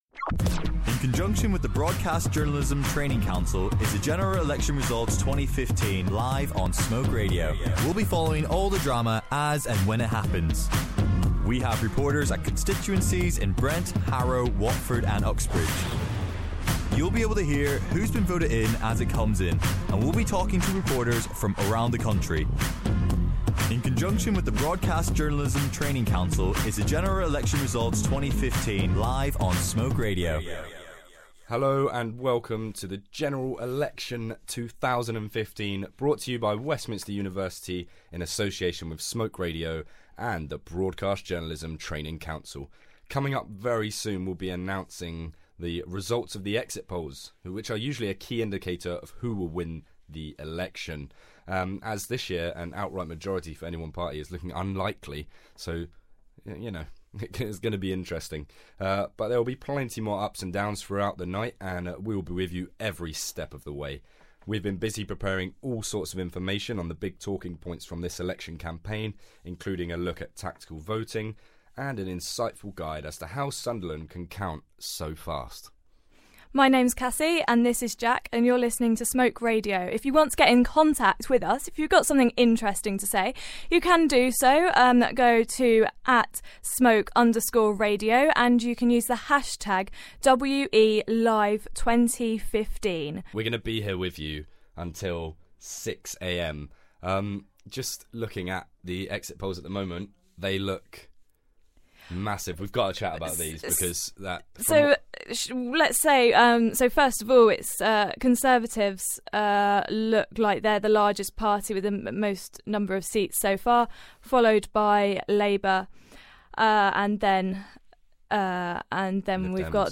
This general election Smoke Radio worked alongside the Broadcast Journalism Training Council to provide coverage from across the country, including our local constituencies.